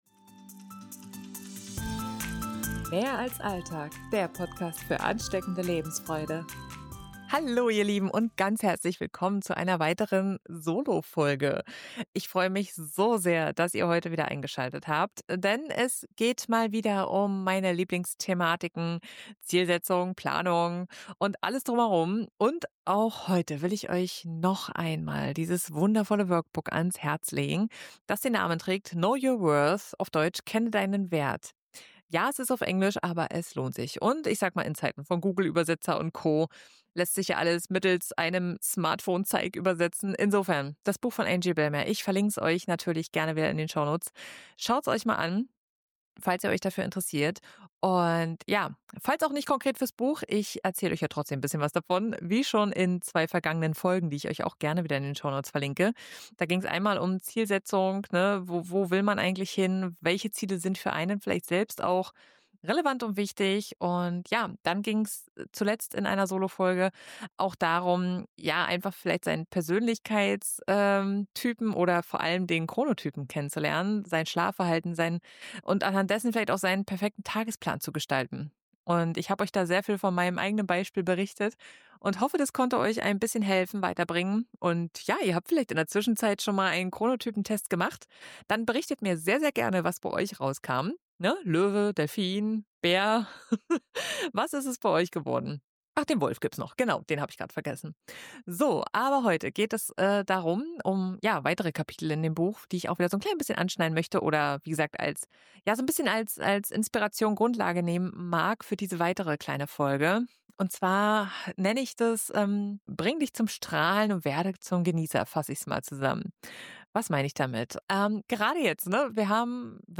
In dieser Solofolge geht es weiter mit dem kleinen Exkurs dazu, deinen Wert mehr zu kennen und dafür einzustehen.